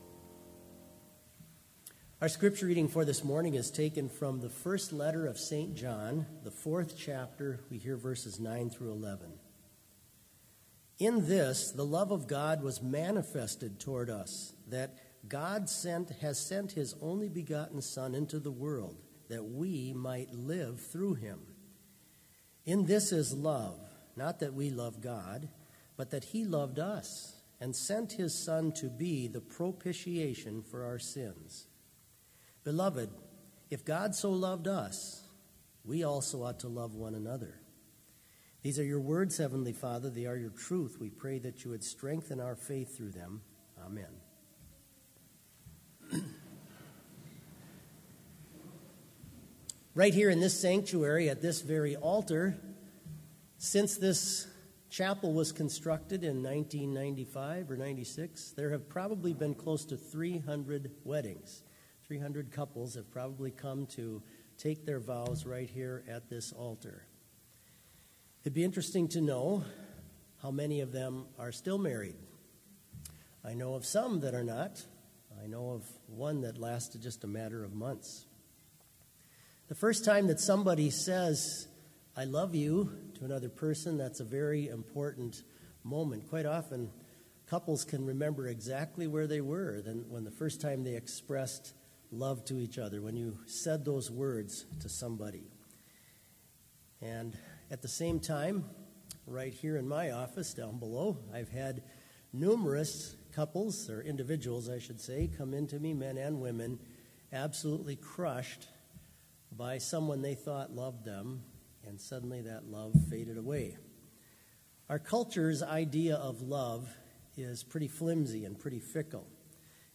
Complete service audio for Chapel - February 22, 2019